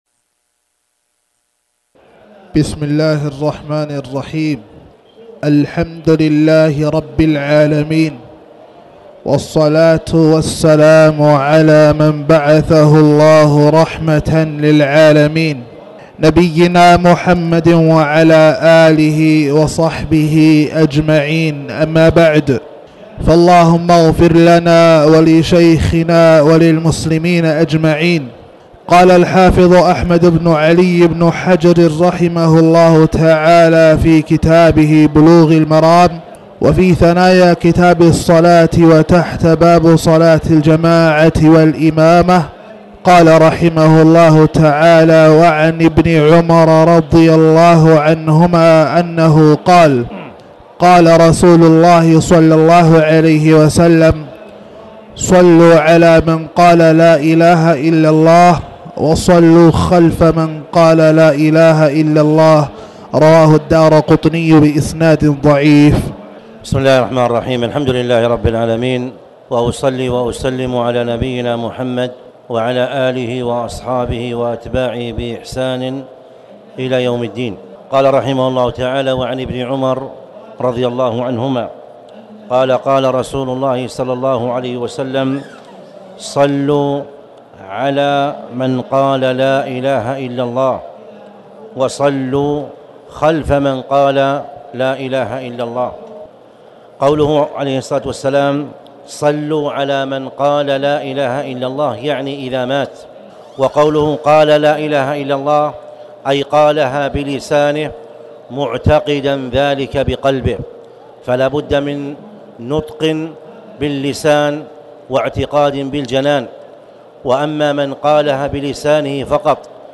تاريخ النشر ٢٧ صفر ١٤٣٩ هـ المكان: المسجد الحرام الشيخ